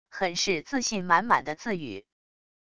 很是自信满满的自语wav音频